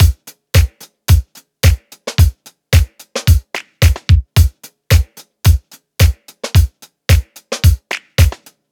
Unison Funk - 9 - 110bpm.wav